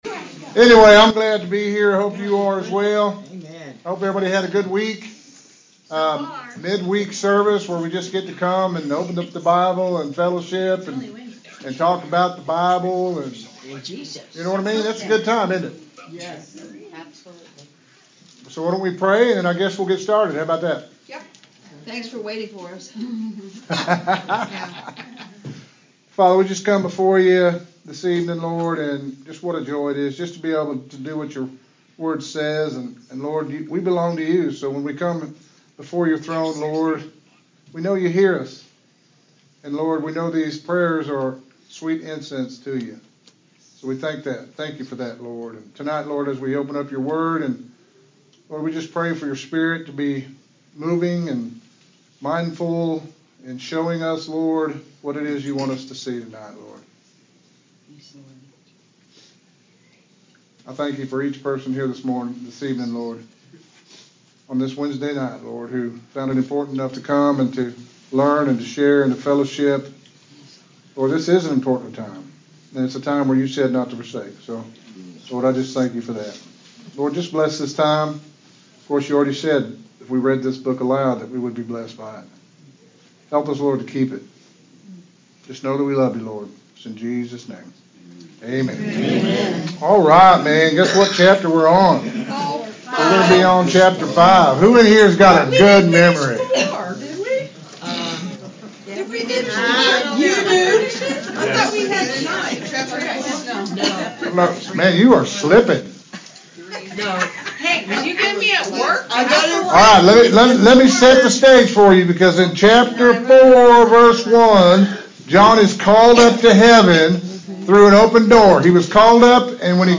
Interactive Bible Study
Sermon